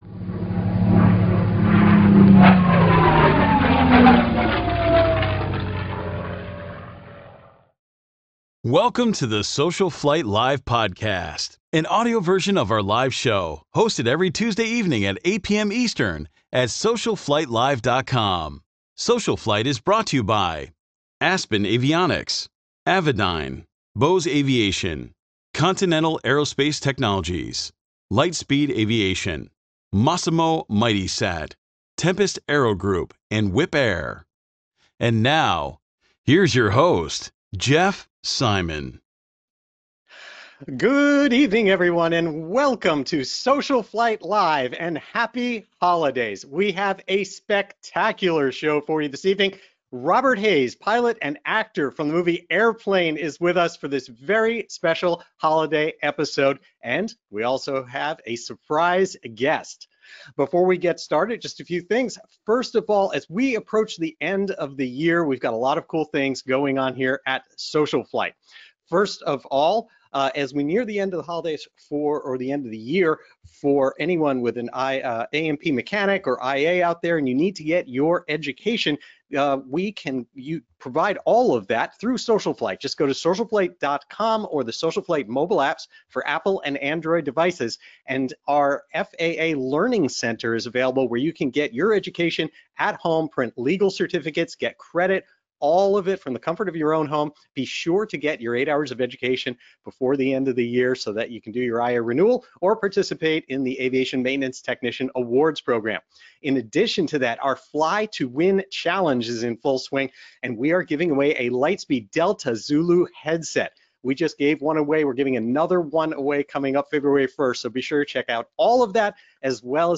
“SocialFlight Live!” is a live broadcast dedicated to supporting General Aviation pilots and enthusiasts during these challenging times.